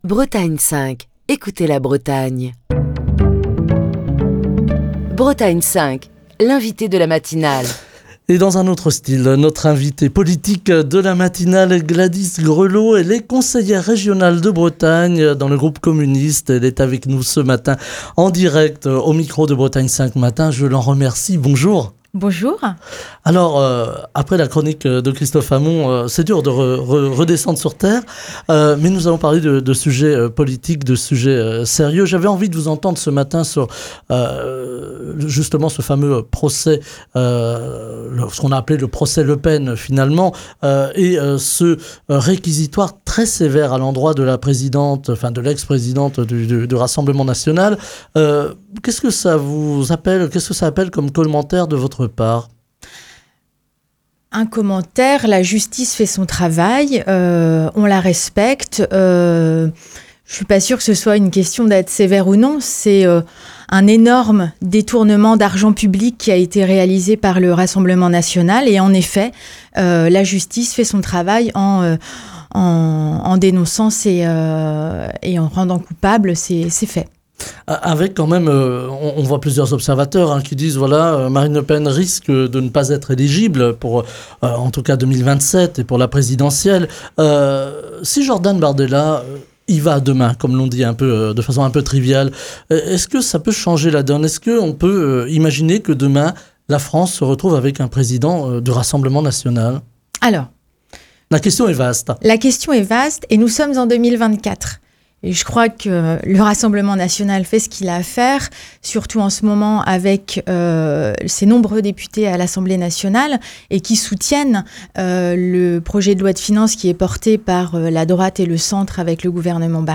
Gladys Grelaud, conseillère régionale de Bretagne (Groupe communiste) et déléguée à la politique de la ville, est l’invitée politique de la matinale de Bretagne 5. Elle aborde les principaux sujets d’actualité, en commençant par le procès des assistants parlementaires du FN, où une peine de cinq ans d’inéligibilité a été requise contre Marine Le Pen.